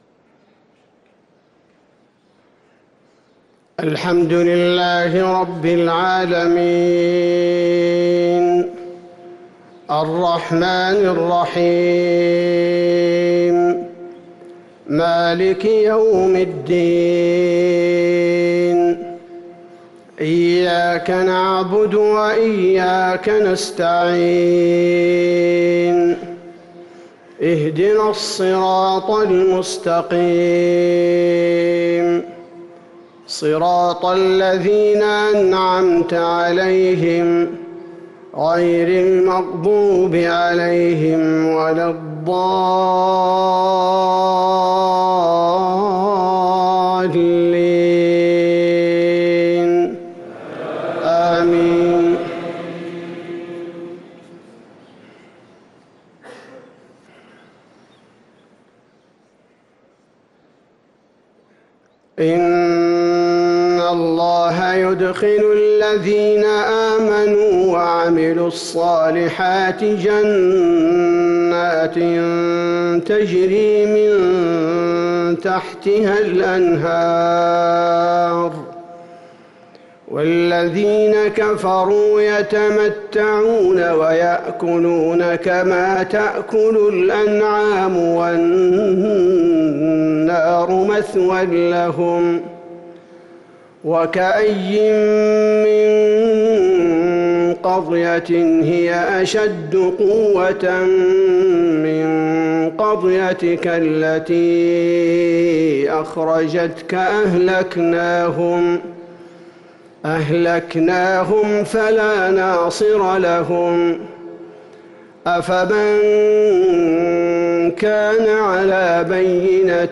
صلاة المغرب للقارئ عبدالباري الثبيتي 20 ربيع الآخر 1445 هـ
تِلَاوَات الْحَرَمَيْن .